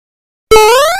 jump2.wav